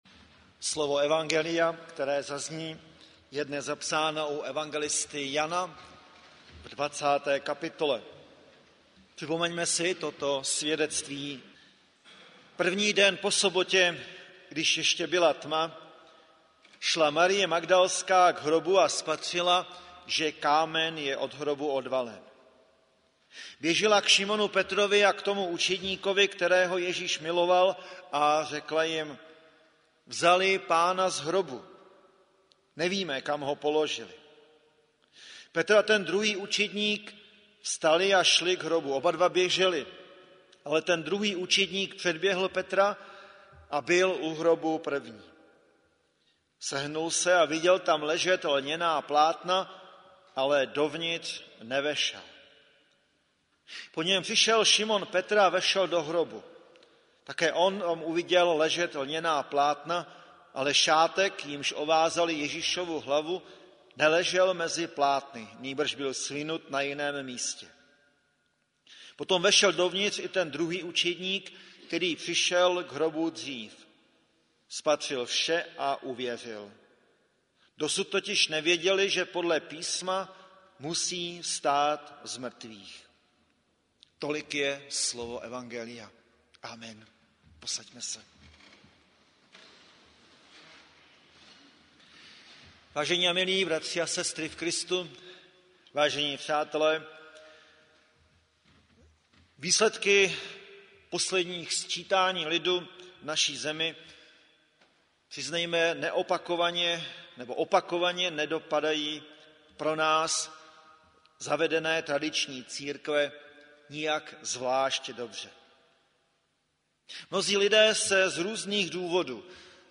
Hudební nešpory - Velikonoční hudba • Farní sbor ČCE Plzeň - západní sbor
Velikonoční hudba pro sbor a varhany
varhany
pěvecký sbor